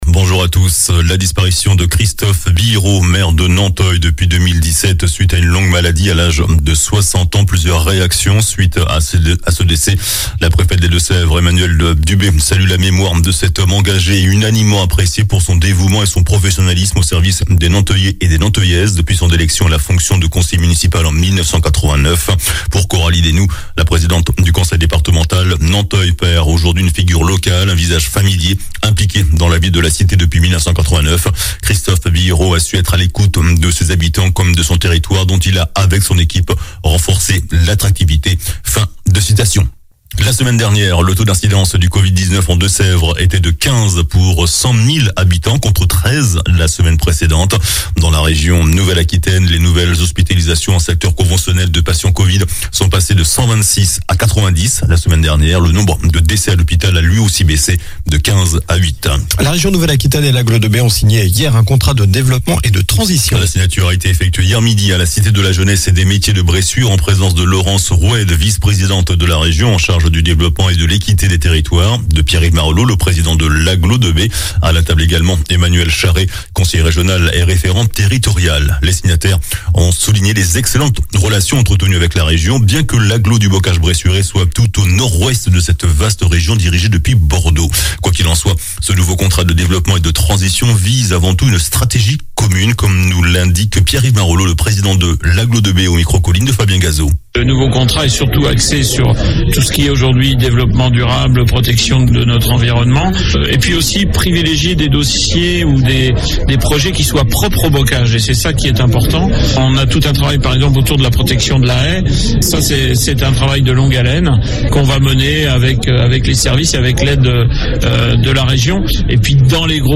JOURNAL DU SAMEDI 25 FEVRIER